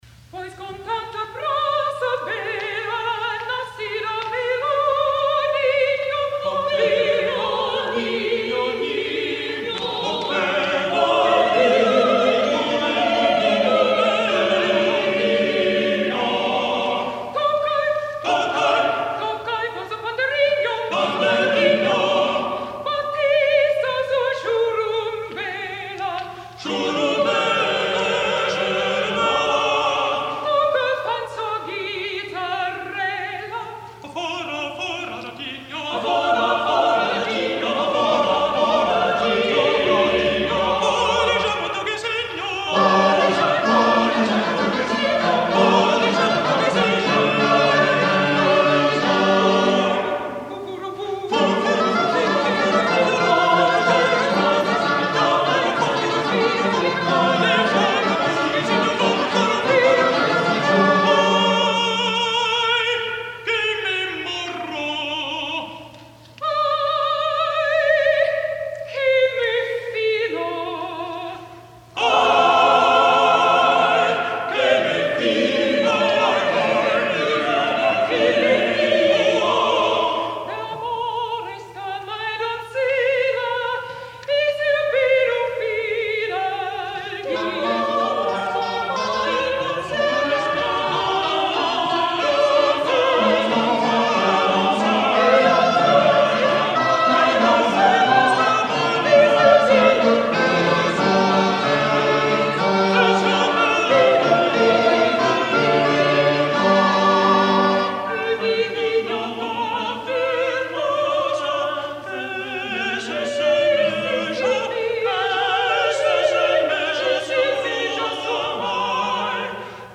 Many of the pieces are lively spiritual villancicos written for Christmas and Corpus Christi, which were especially requested by the authorities and much appreciated by the populace.
soprano
countertenor